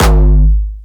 Jumpstyle Kick Solo
4 G#1.wav